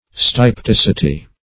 Search Result for " stypticity" : The Collaborative International Dictionary of English v.0.48: Stypticity \Styp*tic"i*ty\, n. [Cf. F. stypticit['e].]